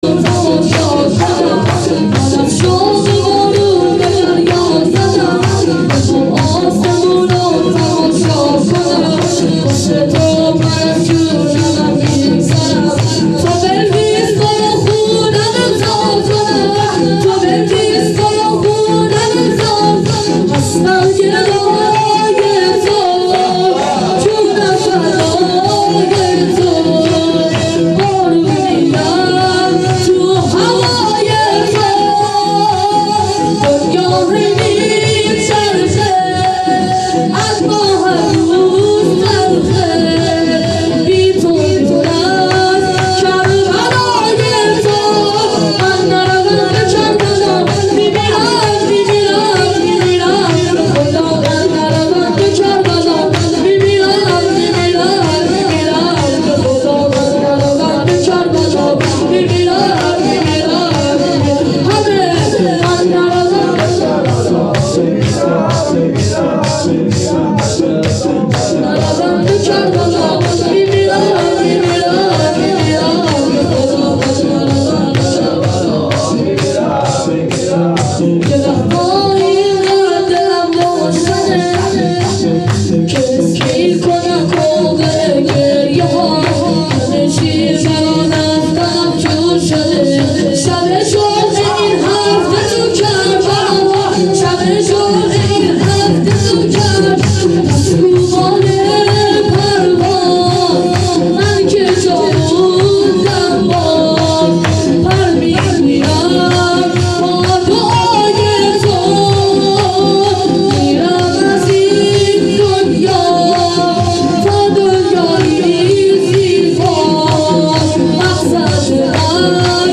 مراسم هفتگی۹۳/۱۱/۱۵
شور